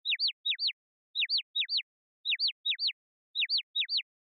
フリー効果音：オノマトペ・めまい｜OtoLogic
「ピヨピヨ」「ピヨる」といった擬音で知られる、マンガ・アニメ・ゲーム風の効果音です。
Onoma-Dizzy02-6(Slow-Loop).mp3